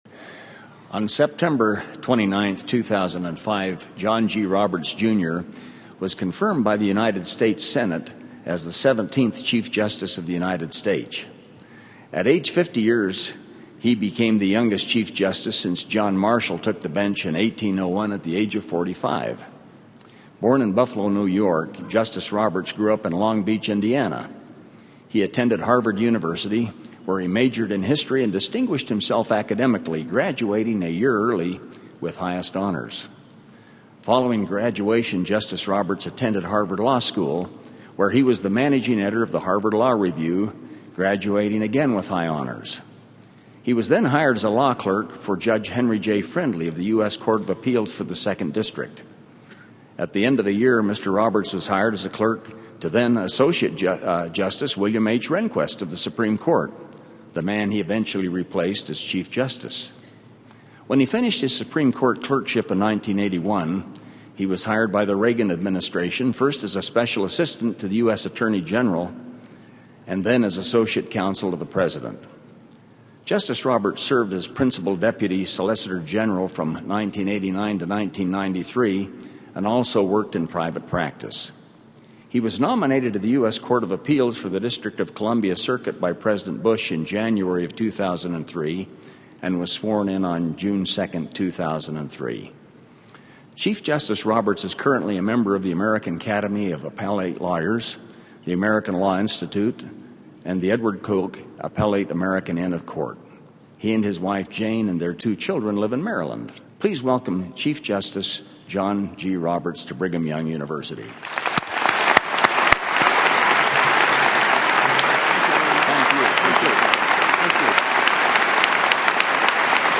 Forum